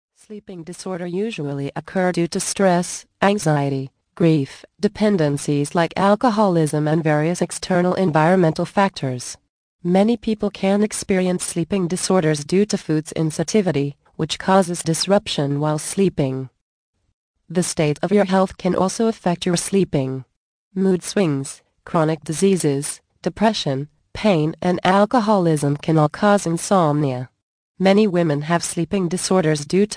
The Magic of Sleep audio book Vol. 14 of 14, 51 min.